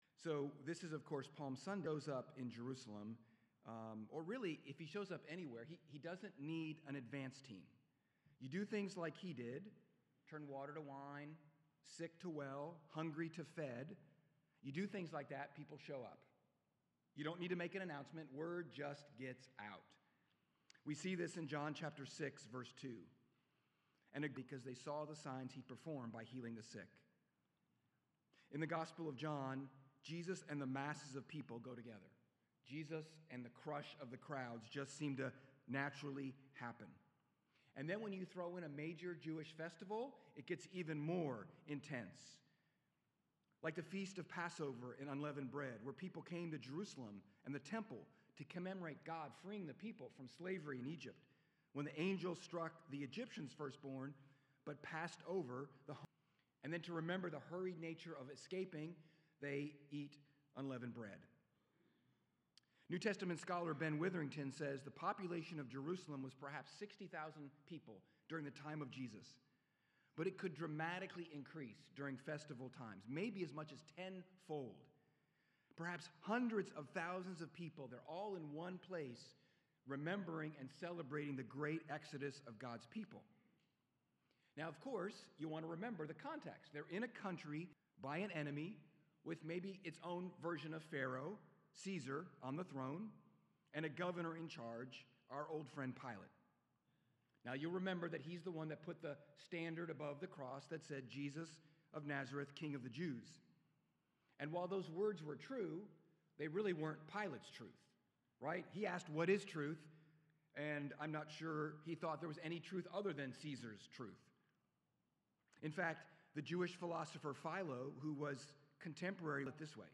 Sermons | Westport Road Church of Christ